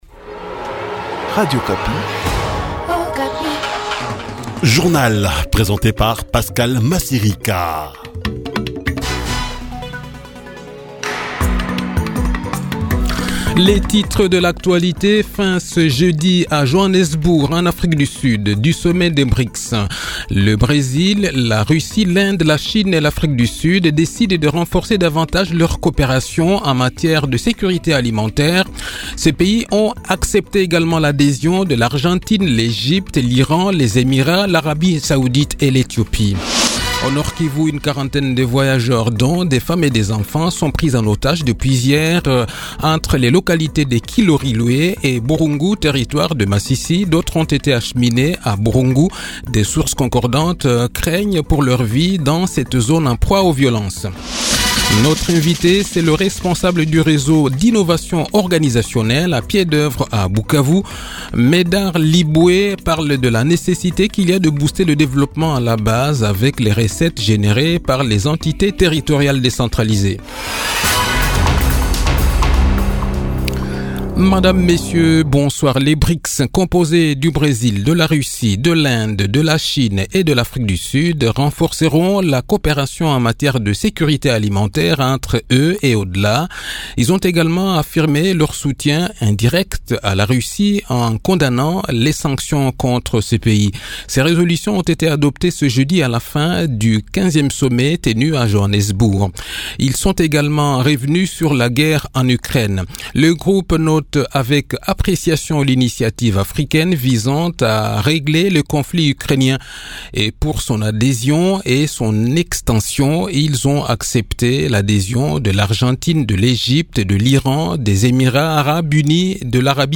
Le journal de 18 h, 24 Aout 2023